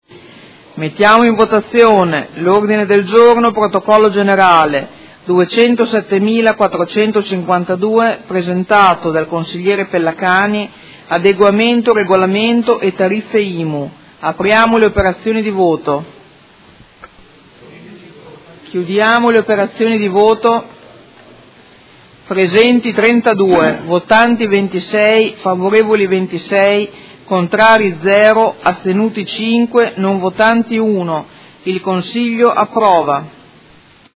Presidente — Sito Audio Consiglio Comunale
Seduta del 20/12/2018. Mette ai voti Ordine del Giorno Prot. Gen. 207452